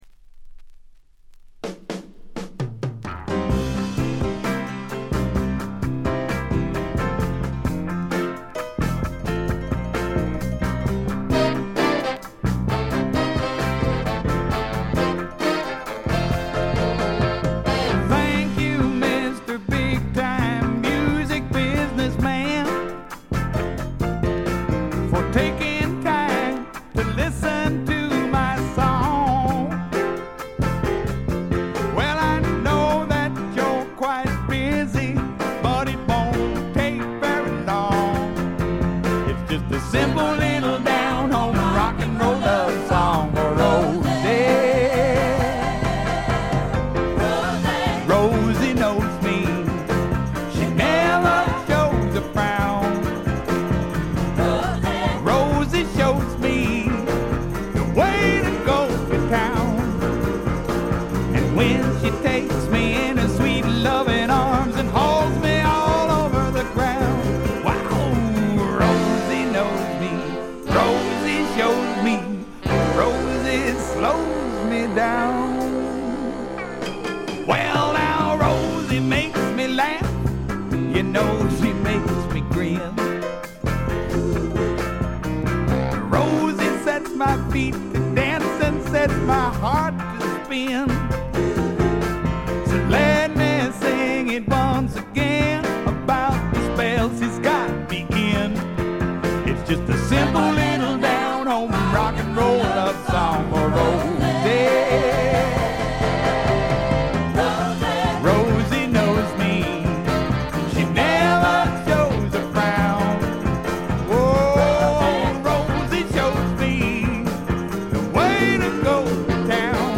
ごくわずかなノイズ感のみ。
スワンプ系シンガーソングライター作品の基本定番。
試聴曲は現品からの取り込み音源です。
Vocals, Acoustic Guitar